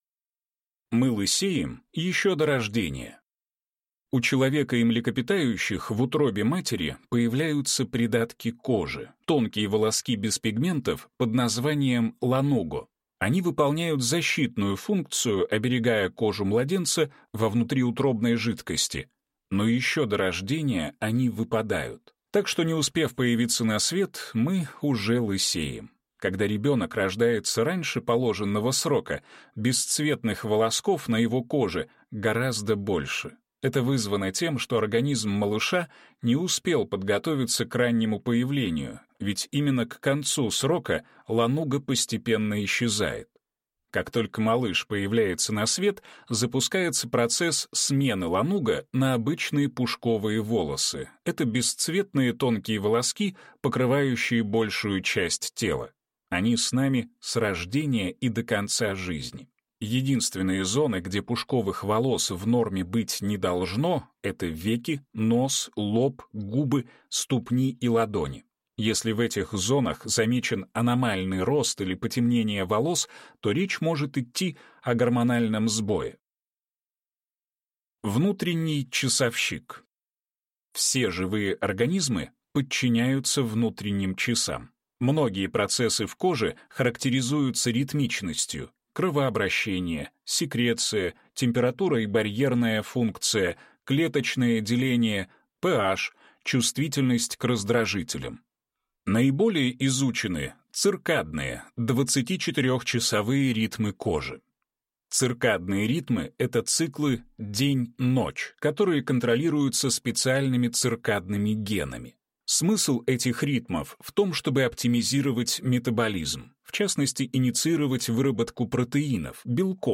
Аудиокнига Удивительные научные факты о коже | Библиотека аудиокниг